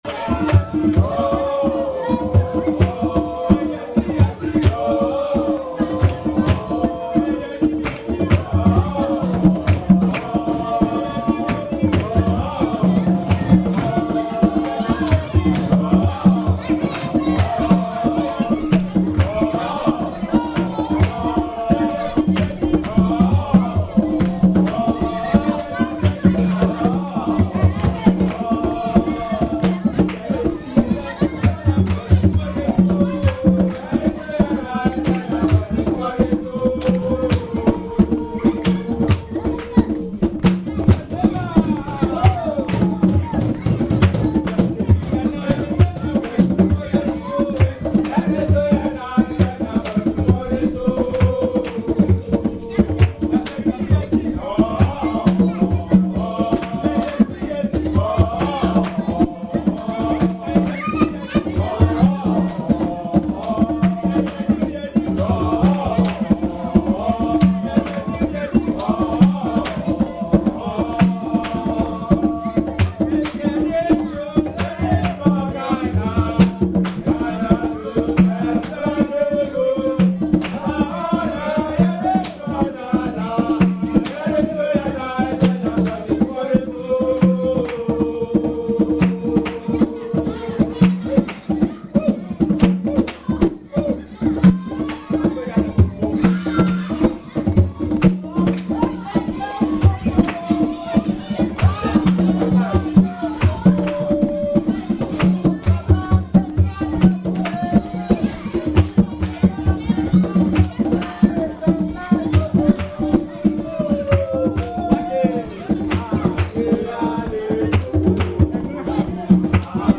The local kids came out and entertained us with their drumming and dancing, which I managed to record.
Here are two recordings from that evening.
Figure 10. The Gazebo where the evening entertainment took place